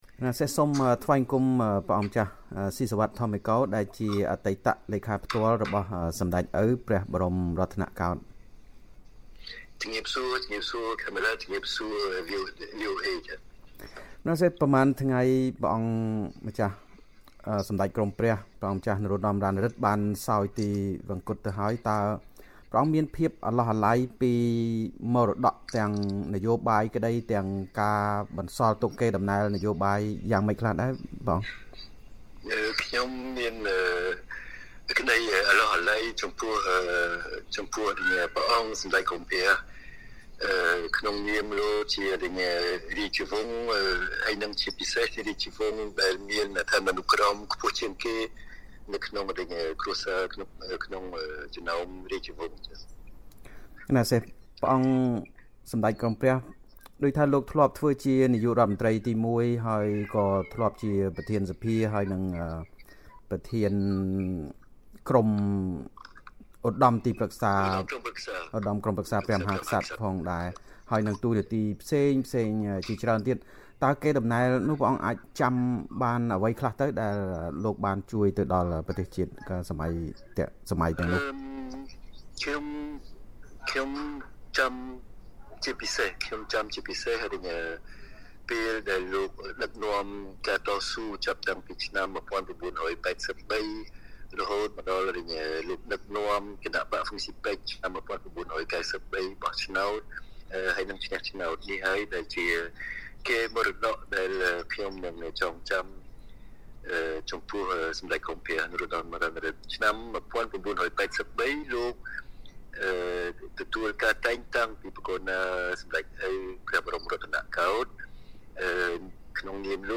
បទសម្ភាសន៍ VOA៖ ការសោយទិវង្គតរបស់ព្រះអង្គម្ចាស់ នរោត្តម រណឫទ្ធិ នាំឲ្យមានការរំឭកកេរដំណែលបង្រួបបង្រួមជាតិ